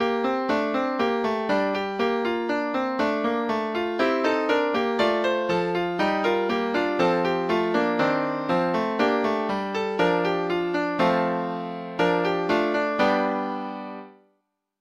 Lytt til data-generert lydfil